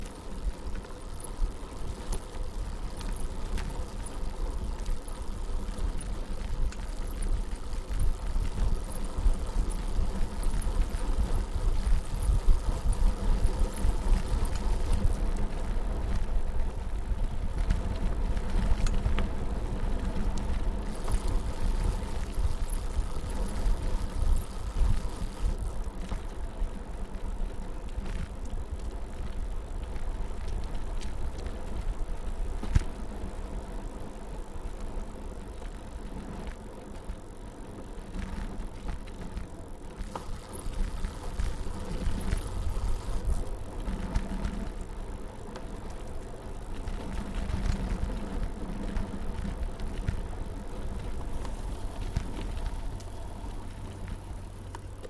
Поездка на скоростном велосипеде с 21 передачей по асфальтированной дороге